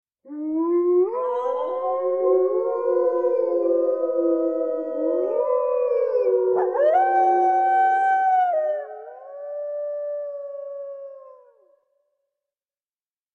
This Collection of Wolf Sounds features Howls, Growls, Pack of Wolves and much more!
Pack-of-wolves-howling.mp3